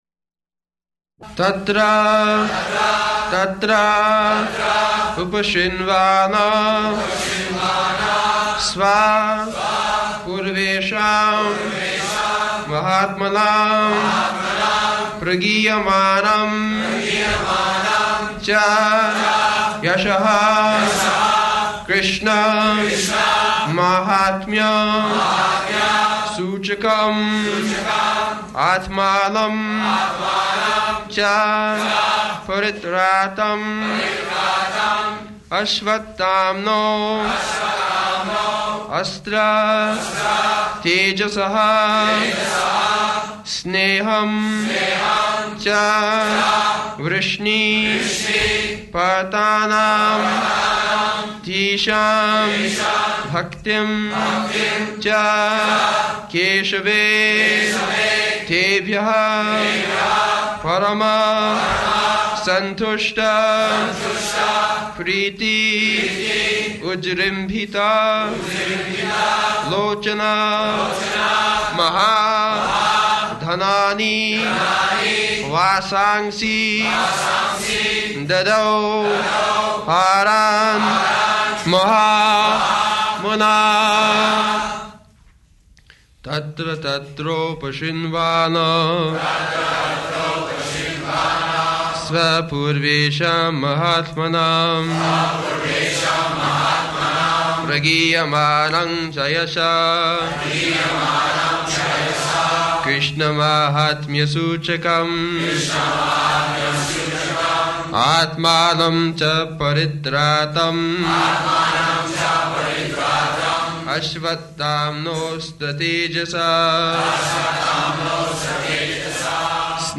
-- Type: Srimad-Bhagavatam Dated: January 10th 1974 Location: Los Angeles Audio file
[Prabhupāda and devotees repeat]